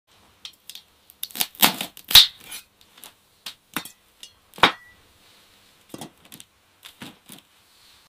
ASMR glass garden vegetables, broccoli